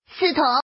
Index of /mahjong_gansu_test/update/1686/res/sfx/woman/